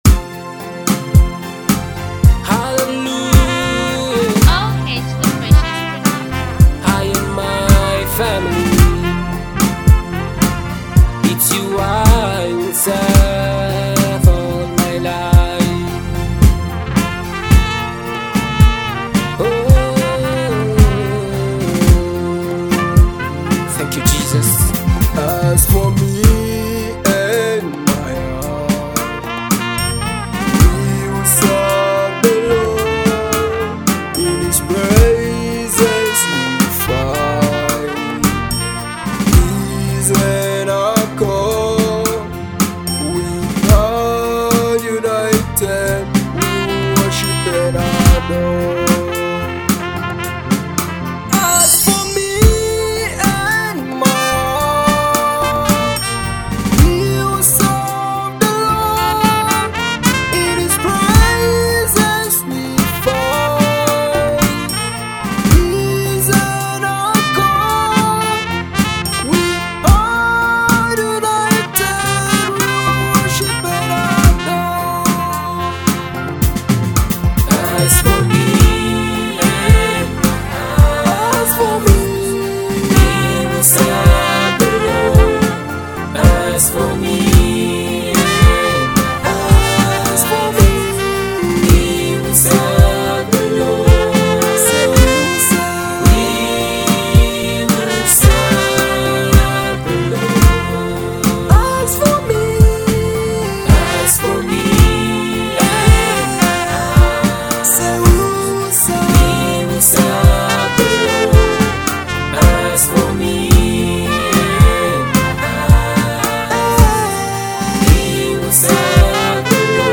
uplifting song